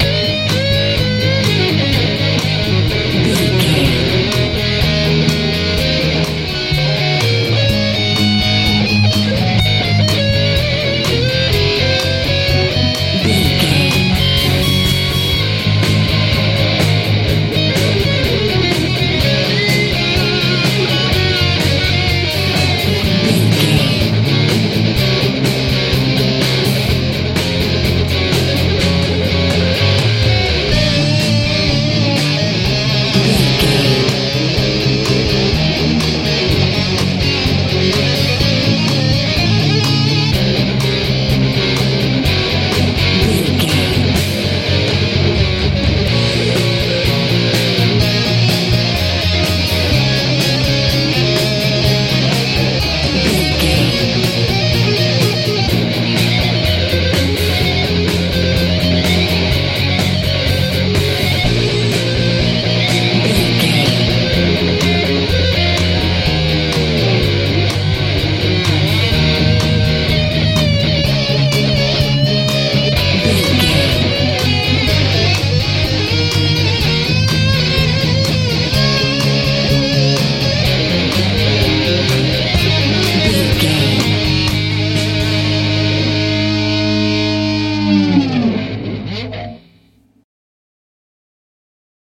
nu mental feeing music
Ionian/Major
A♯
strange
powerful
bass guitar
drums
electric guitar
driving
energetic
cheerful/happy